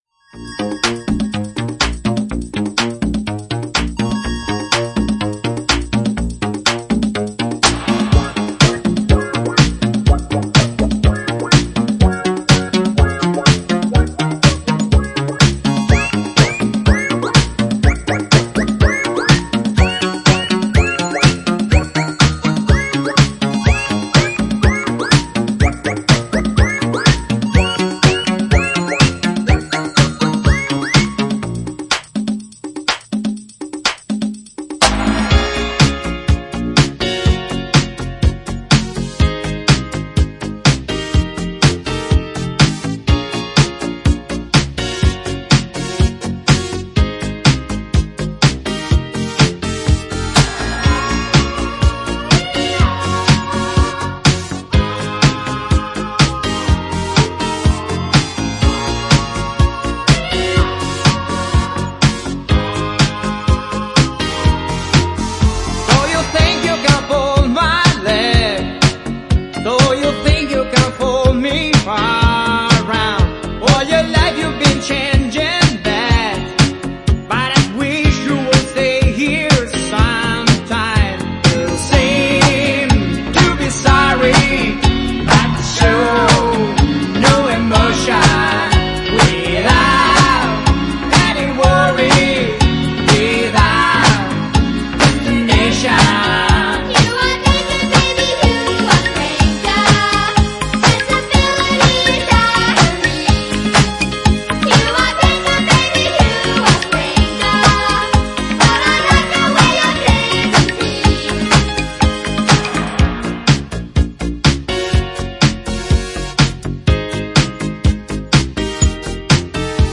Extended Mix Remastered